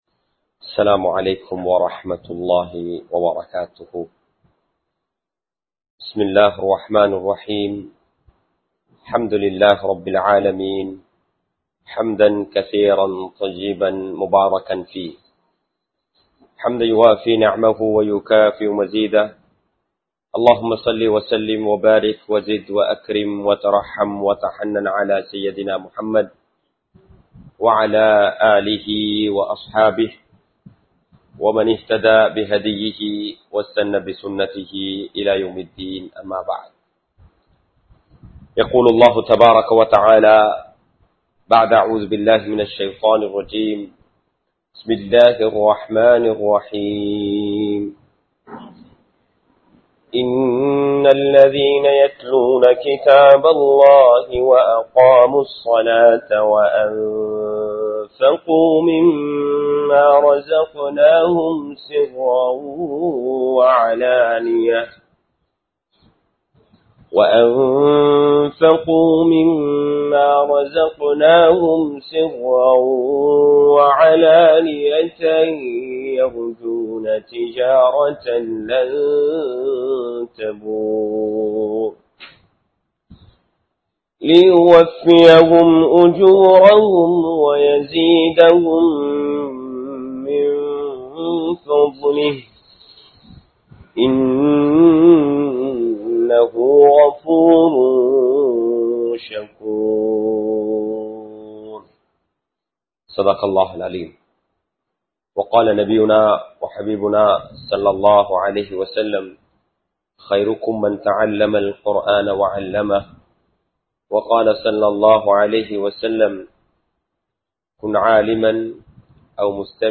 அல்குர்ஆனை நெருங்குவோம் | Audio Bayans | All Ceylon Muslim Youth Community | Addalaichenai
Pallimulla Jumua Masjith